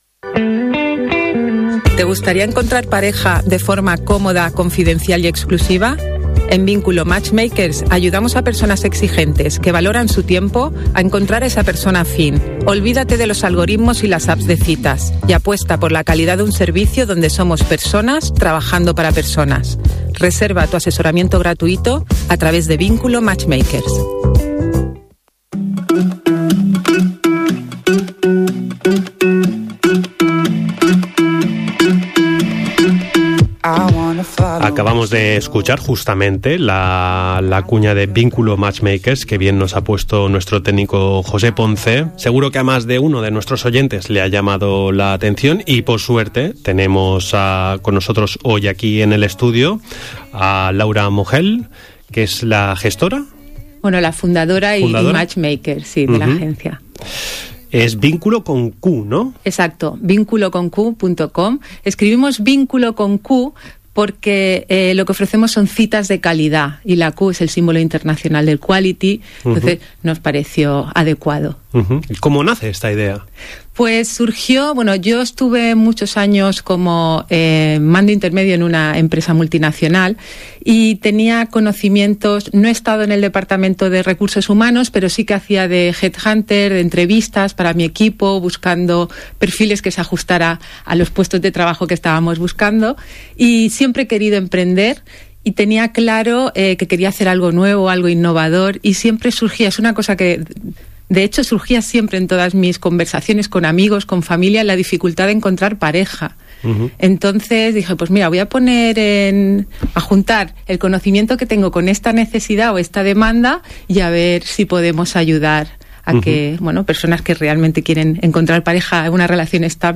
Entrevista-a-Vinqulo-COPE.mp3